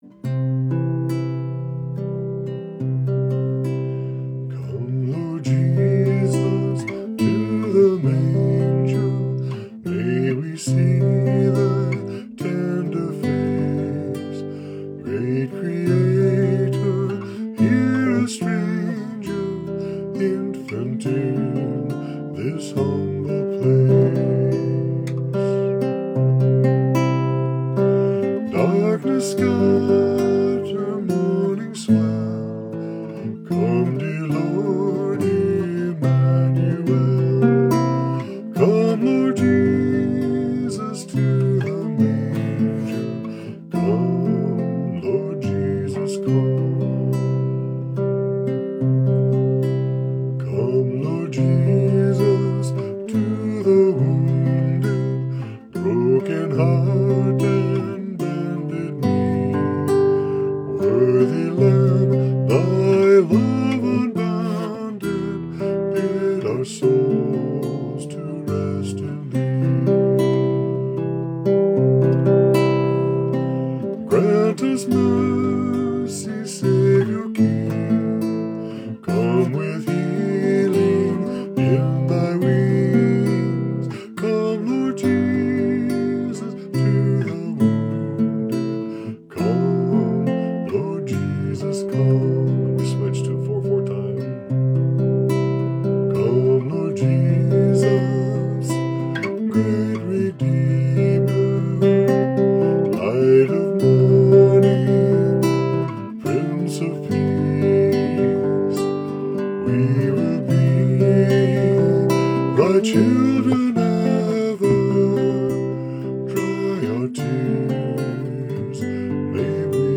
Duet
Vocal Solo
Come Lord Jesus arranged in a 3/4 and then 4/4 Celtic style for Guitar and vocal duet.